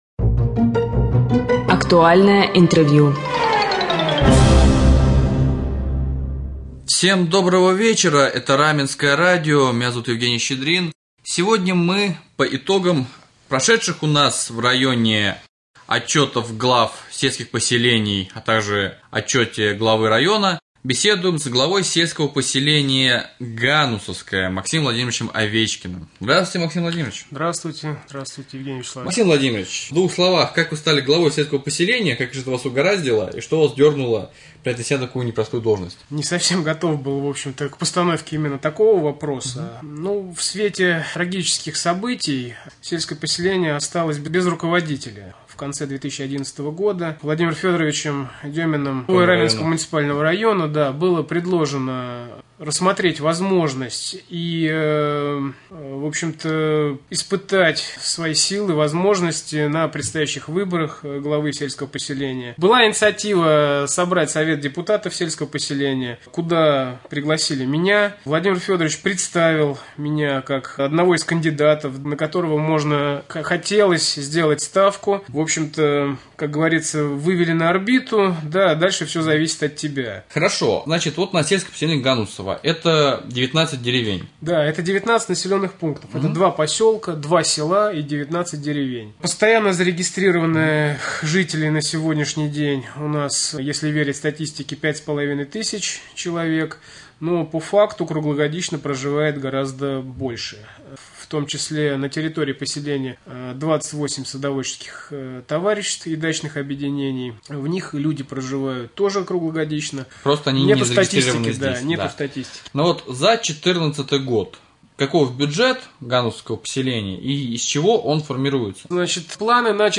Разговор с главой с.п. Ганусовское
1.Актуальное-интервью.mp3